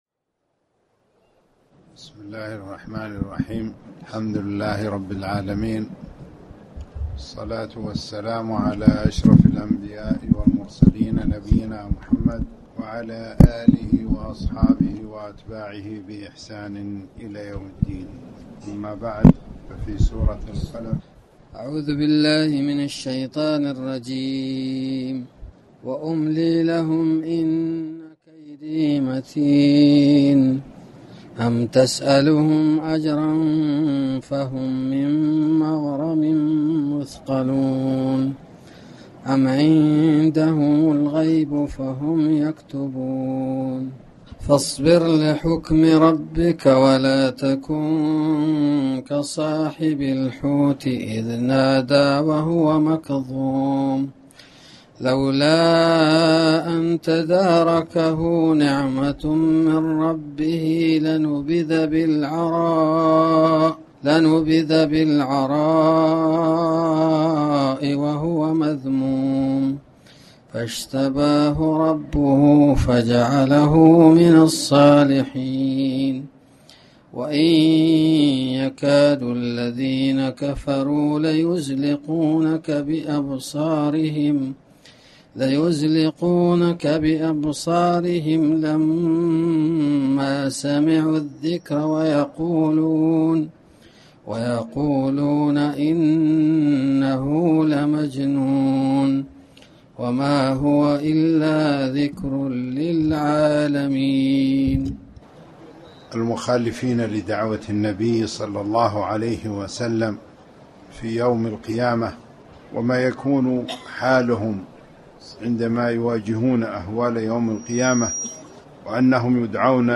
تاريخ النشر ١٤ ربيع الثاني ١٤٣٩ هـ المكان: المسجد الحرام الشيخ